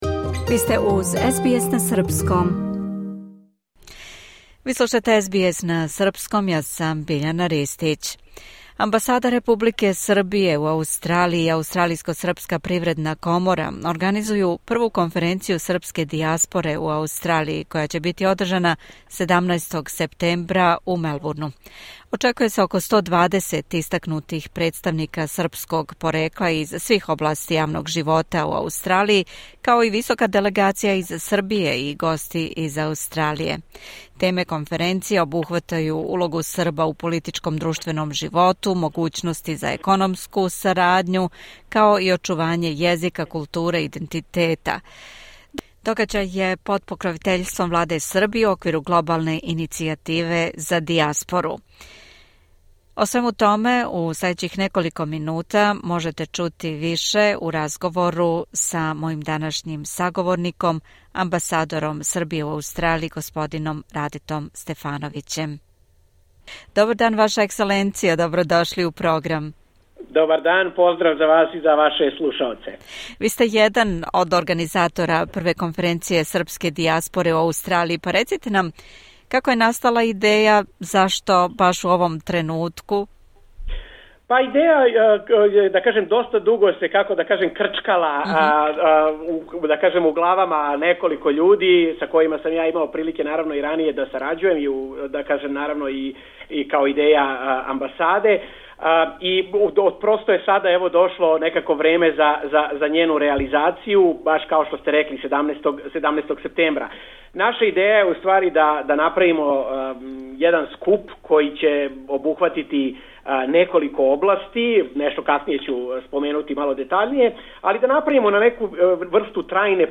Амбасадор Србије у Аустралији госородин Раде Стефновић рекао је више детаља о том догађају.